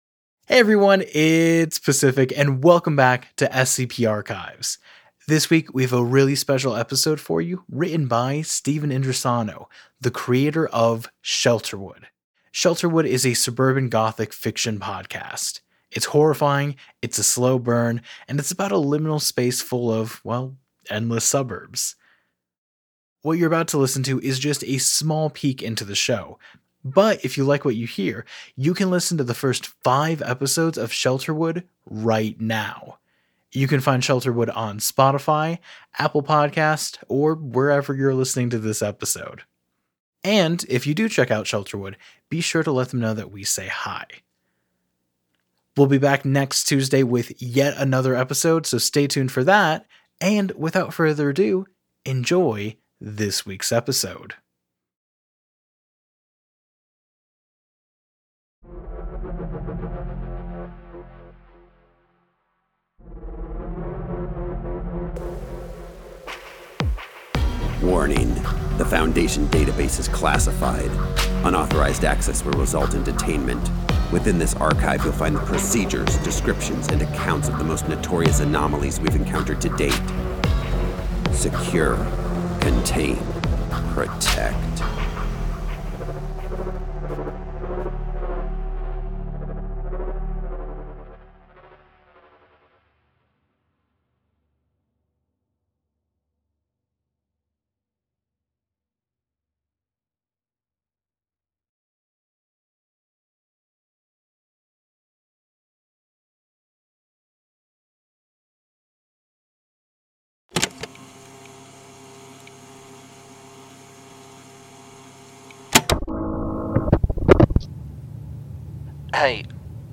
Arts, Society & Culture, Tv & Film, Drama, Fiction, Science Fiction